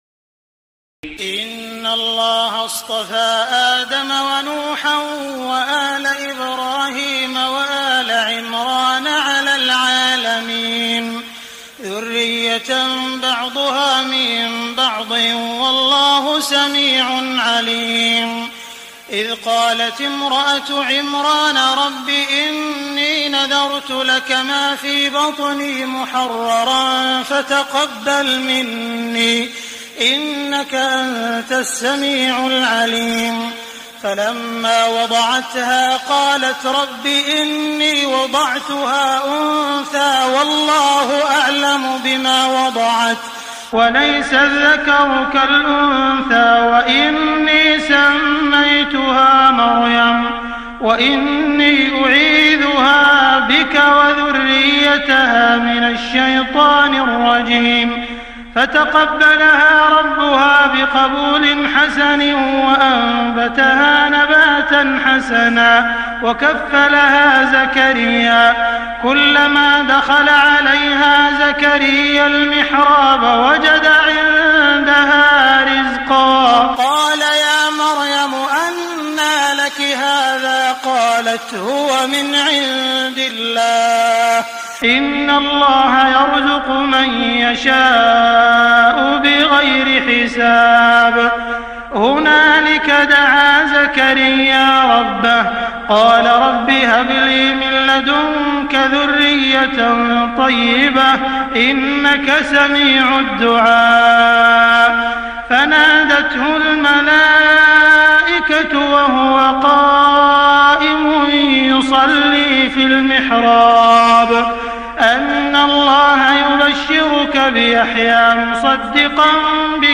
تهجد ليلة 23 رمضان 1422هـ من سورة آل عمران (33-92) Tahajjud 23 st night Ramadan 1422H from Surah Aal-i-Imraan > تراويح الحرم المكي عام 1422 🕋 > التراويح - تلاوات الحرمين